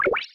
Heal3.wav